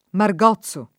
Margozzo [ mar g0ZZ o ] → Mergozzo